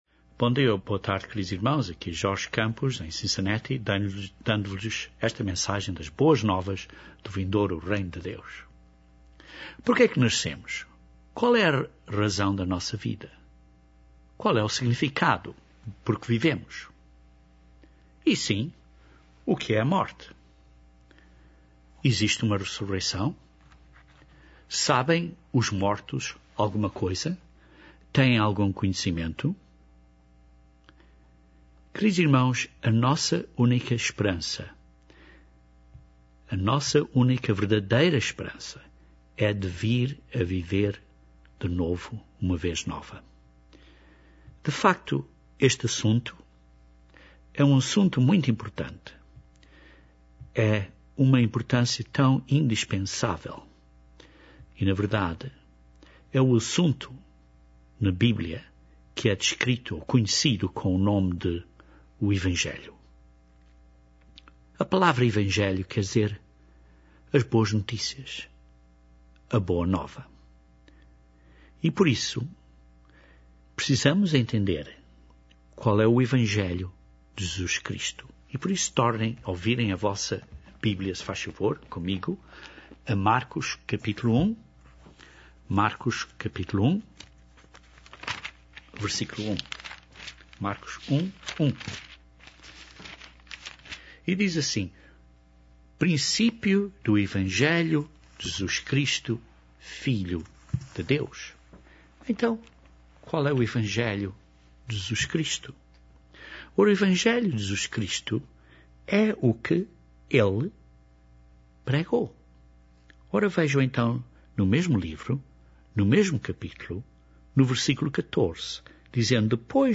Este sermão descreve claramente a doutrina da ressurreição dos mor